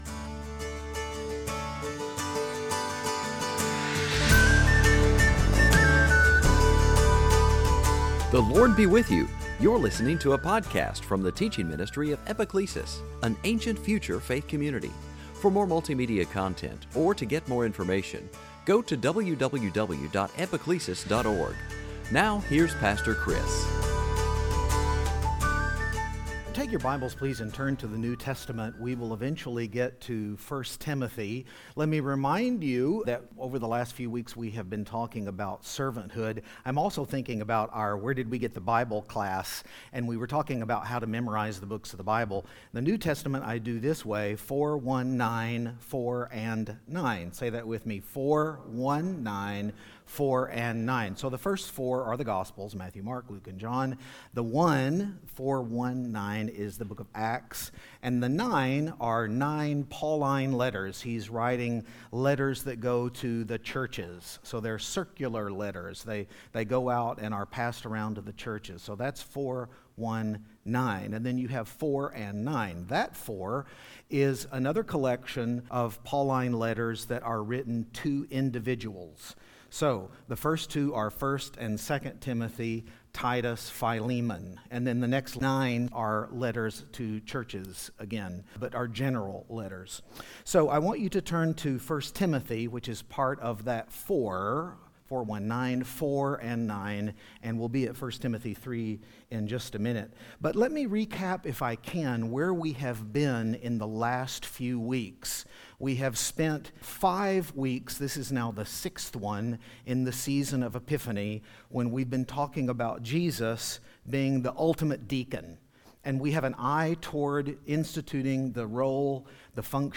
2020 Sunday Teaching deacons overseers Paul servant leaders Timothy Epiphany